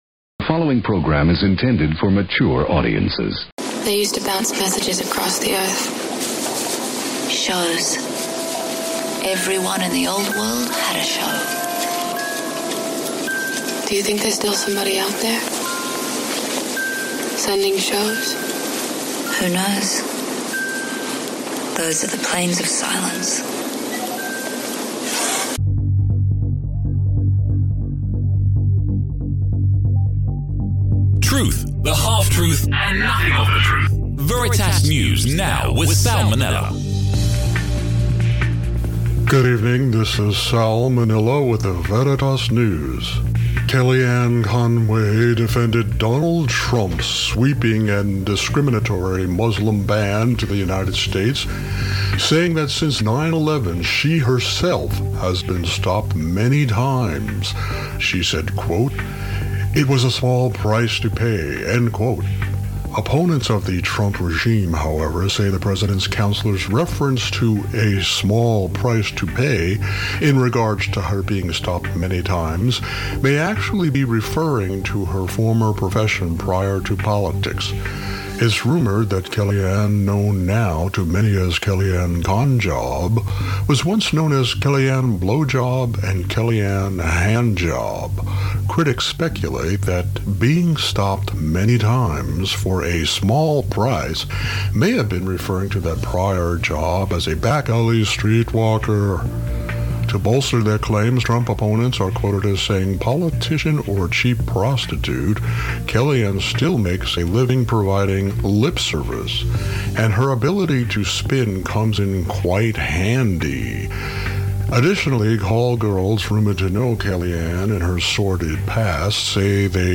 Billy Eichner puts Keegan-Michael Key through the paces in a game-show spoof on how easy it is to get a gun in America.